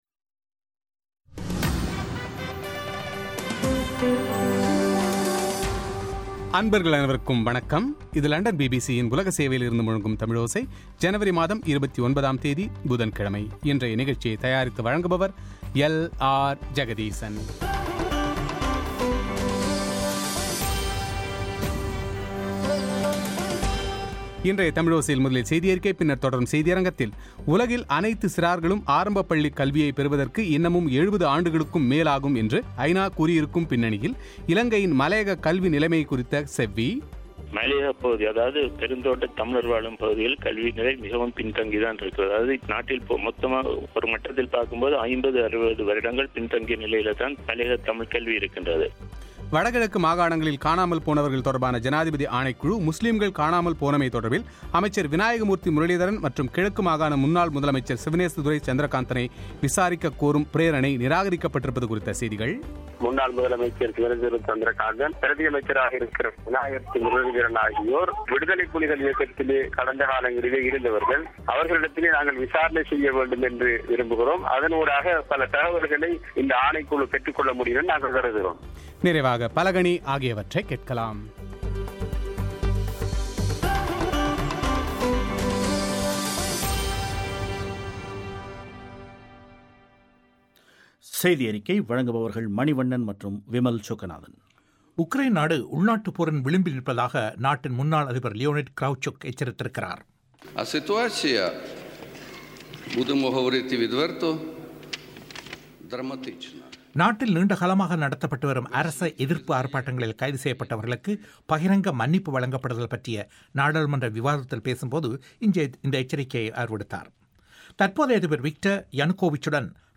உலகில் அனைத்து சிறார்களும் ஆரம்பப் பள்ளிக் கல்வியை பெறுவதற்கு இன்னமும் 70 ஆண்டுகளுக்கும் அதிகம் ஆகும் என்று ஐநா கூறியுள்ள பின்னணியில் இலங்கையின் மலையக கல்விநிலைமை குறித்த செவ்வி;